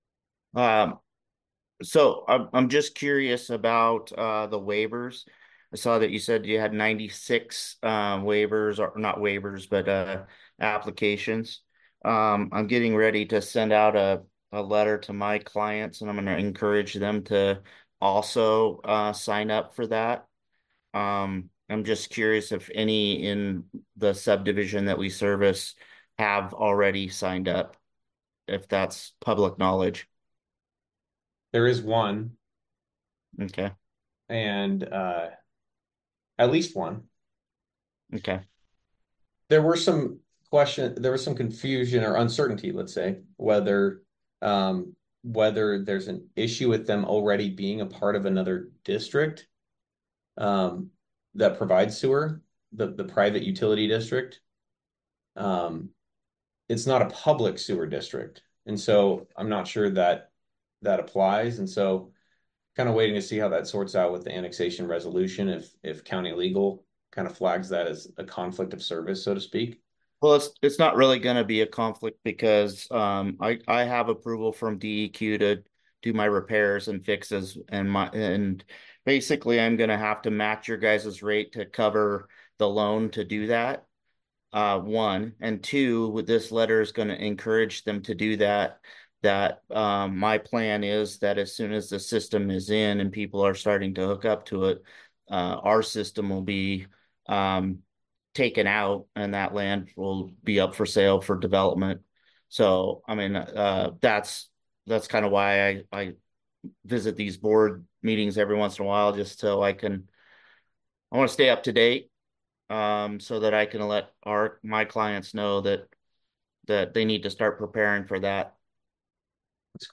The second one is from statements he made at the Terrebonne Sanitary District board meeting on August 15th, 2024.